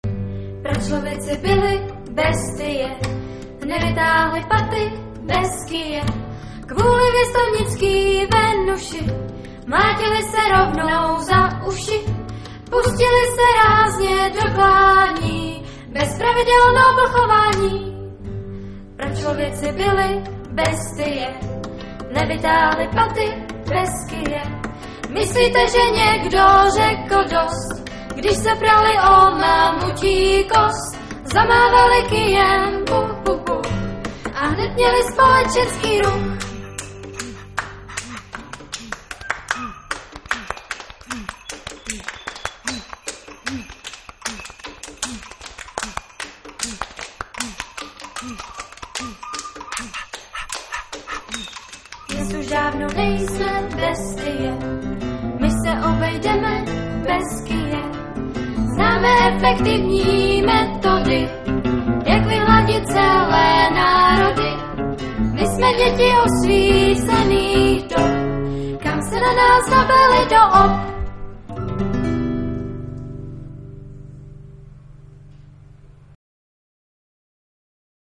TÁBOR/SEZIMOVO ÚSTÍ - V loňském roce na táborské Bambiriádě 2006 opět vystoupili vítězové Dětské porty z Českého Krumlova - kapela LÉTAVICE z Českých Budějovic